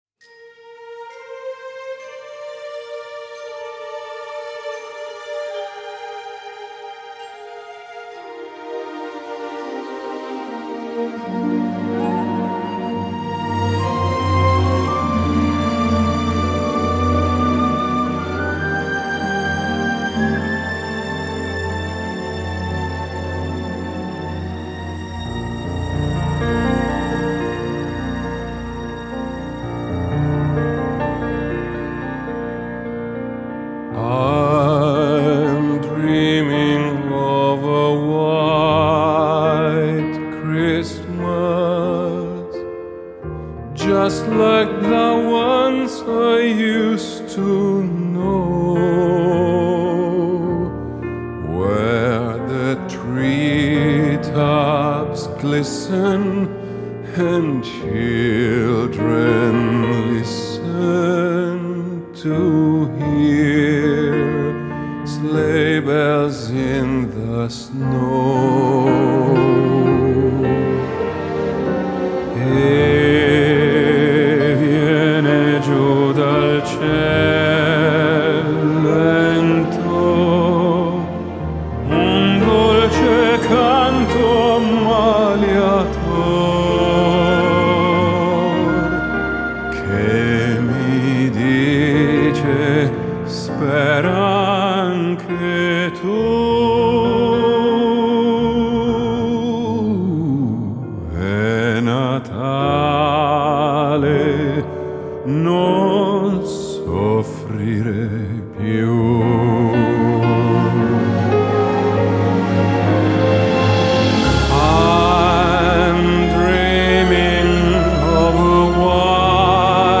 Classical Crossover, Pop, Holiday, Religious